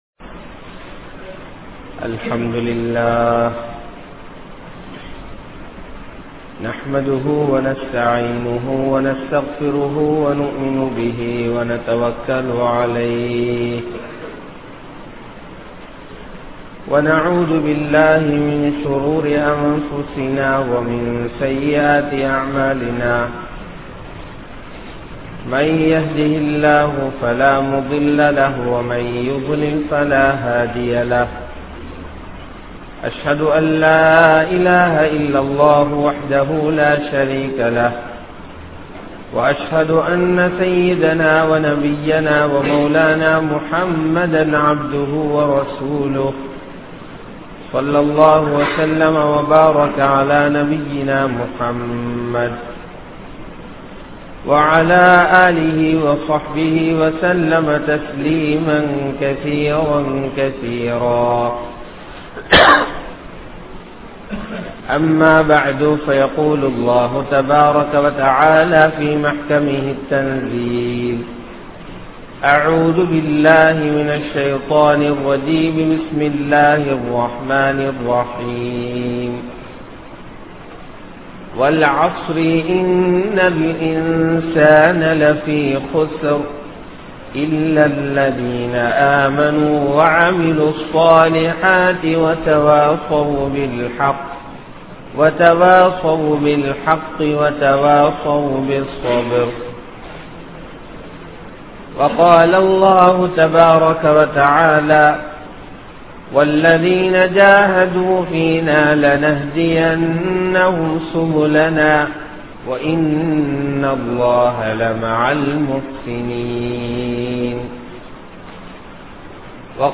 Nastawaali Yaar? (நஷ்டவாளிகள் யார்?) | Audio Bayans | All Ceylon Muslim Youth Community | Addalaichenai